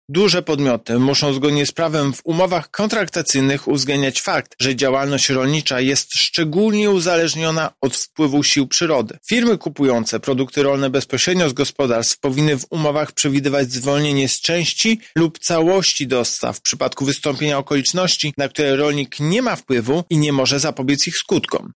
Jeśli rolnicy dostarczą mniej produktów grożą im kary finansowe – mówi prezes UOKiK Tomasz Chróstny: